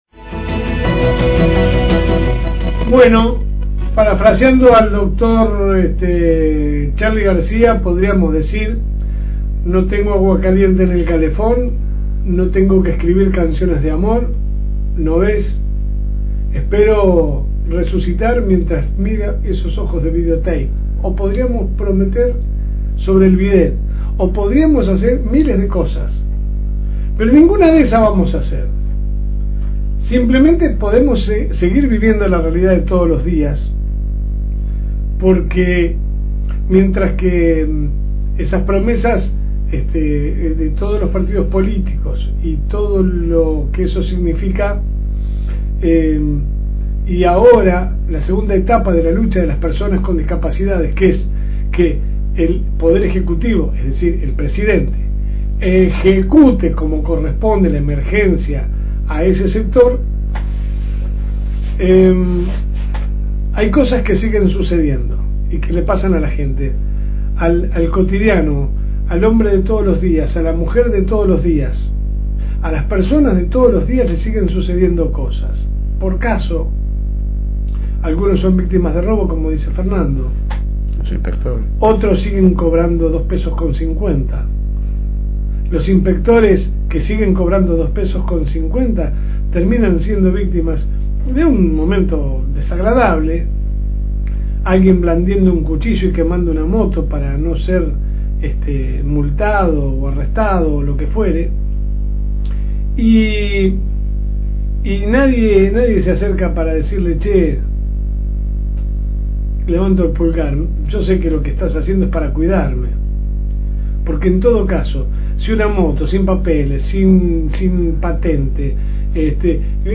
Compartimos la última editorial